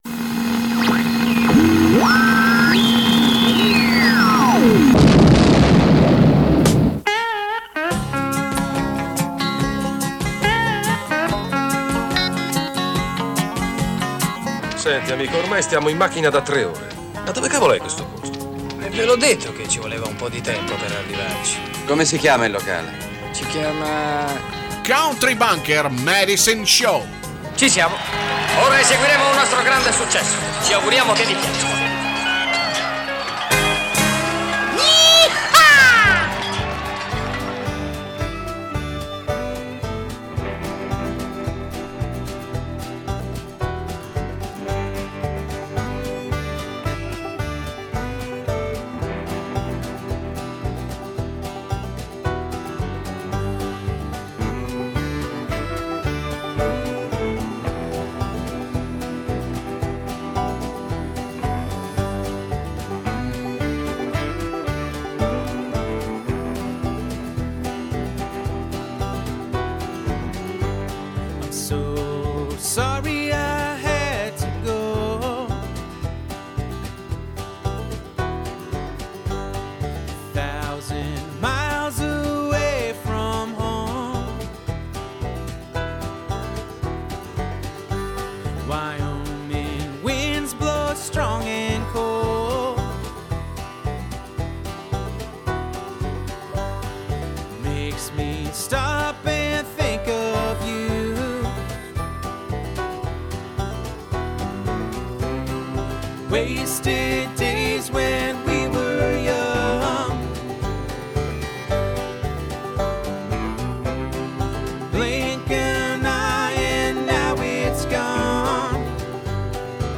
Both kind of music: Country&Western